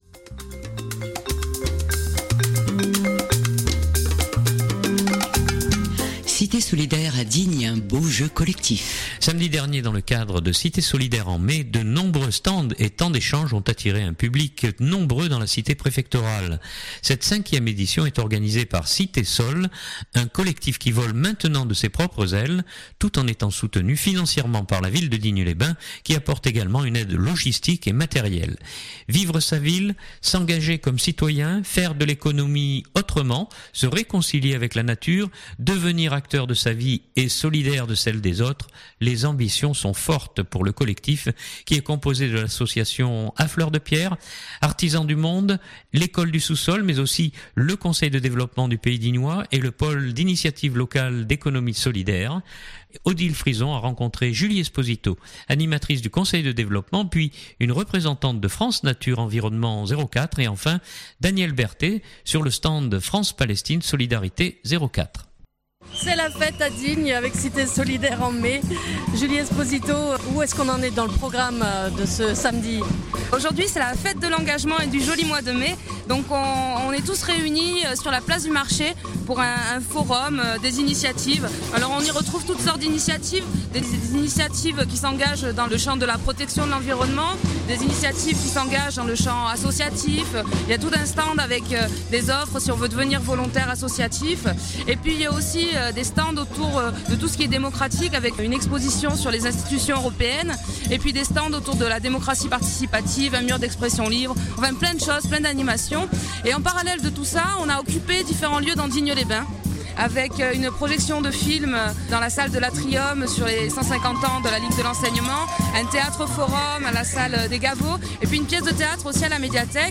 puis une représentante de France Nature Environnement 04